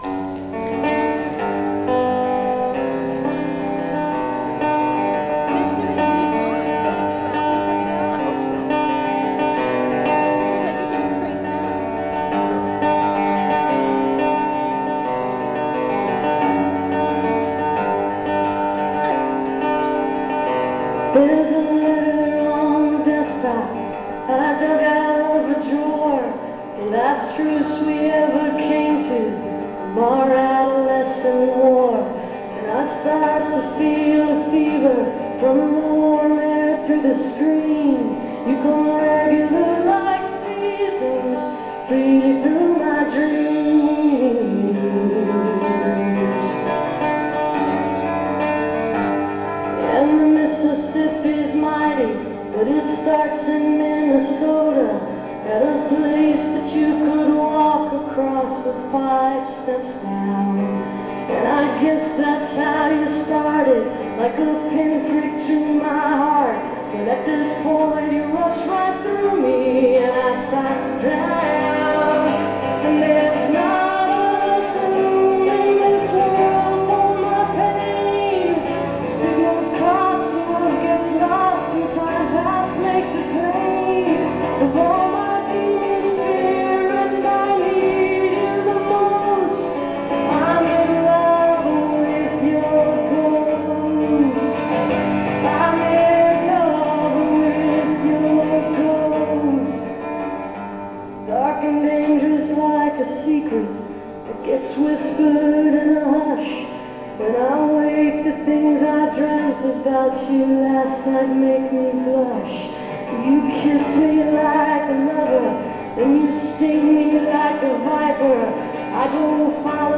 (fades out)